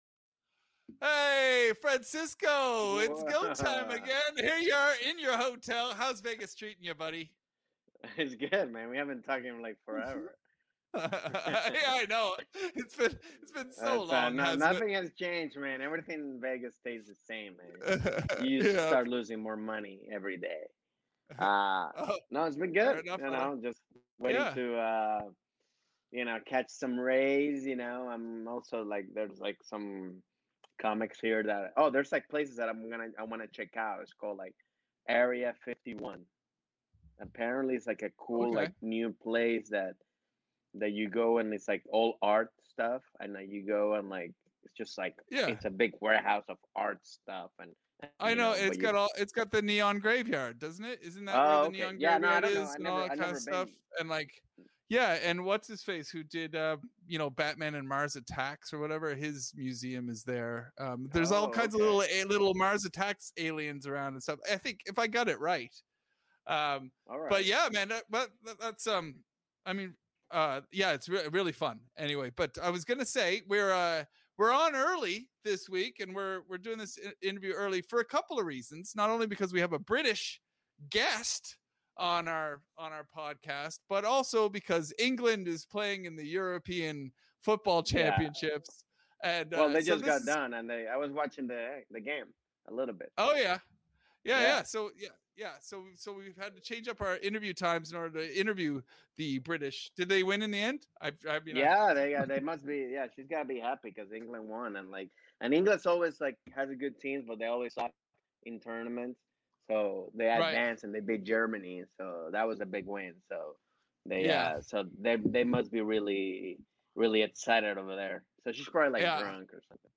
This week British comedian, writer, actor TIFF STEVENSON drops by to chat with the guys about the England vs Germany match , the California coast, and getting an airbag to the face.